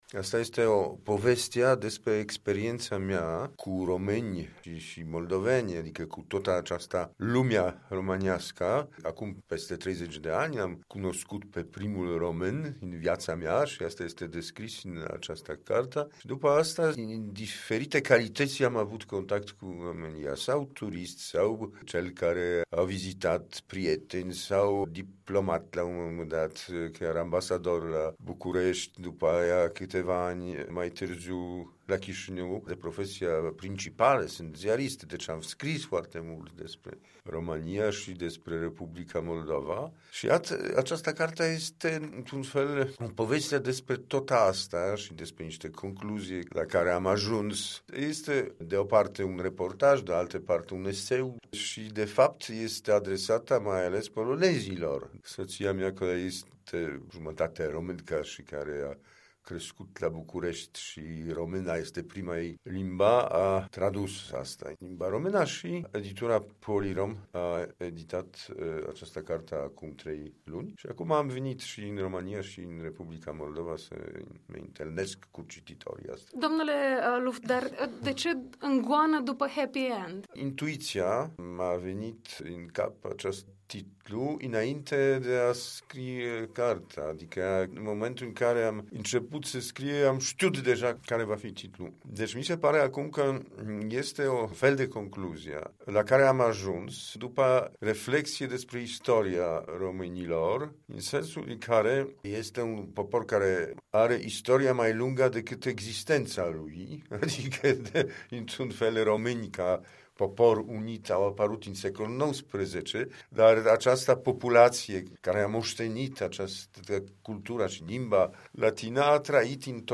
Interviu cu fostul ambasador al Poloniei la București și la Chișinău